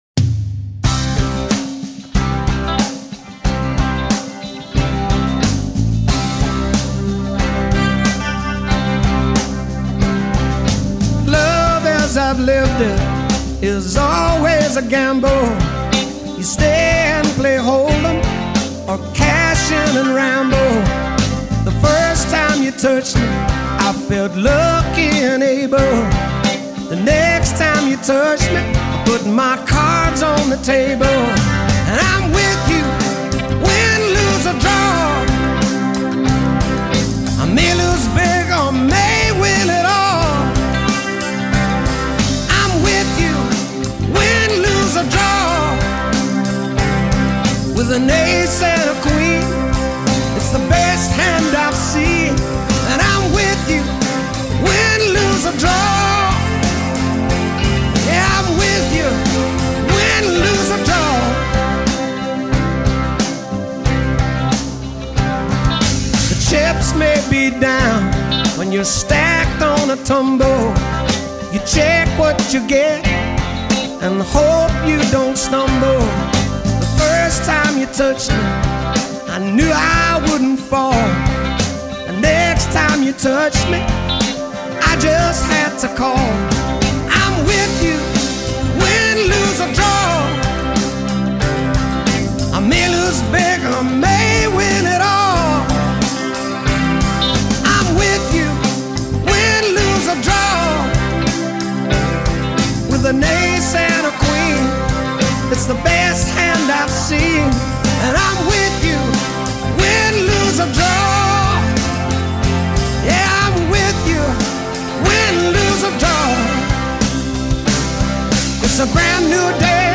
Rock.